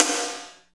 47.09 SNR.wav